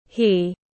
Anh ấy tiếng anh gọi là he, phiên âm tiếng anh đọc là /hiː/.
He /hiː/